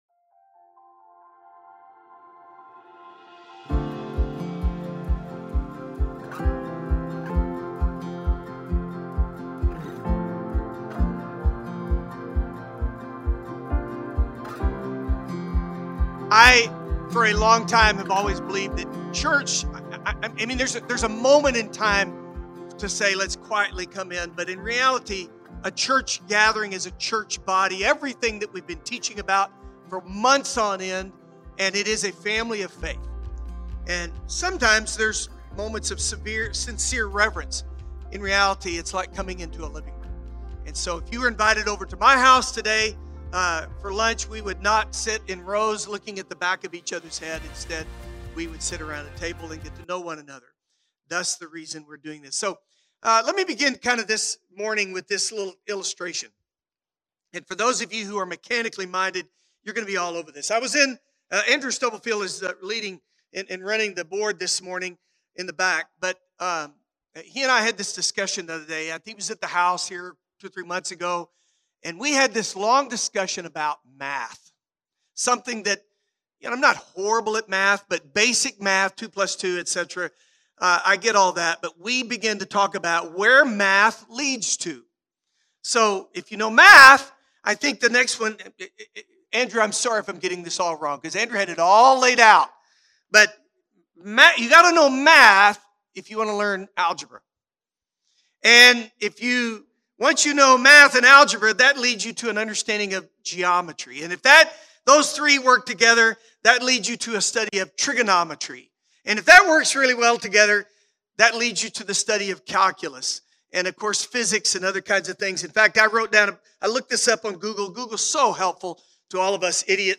Download the sermon notes (adult version)